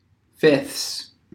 Ääntäminen
Ääntäminen US : IPA : /fɪfθs/ US US : IPA : /fɪθs/ Tuntematon aksentti: IPA : /fɪfts/ Haettu sana löytyi näillä lähdekielillä: englanti Käännöksiä ei löytynyt valitulle kohdekielelle.